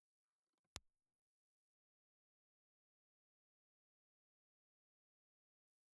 Ribbon
Impulse Response file of the Zepyr 30RA ribbon microphone.
Zephyr_R30_IR.wav
The Zephyr microphone in our collection sounds rather dark which is due to a combination of a large proximity effect and a reduced top end from the high impedance EE24/25 sized transformer.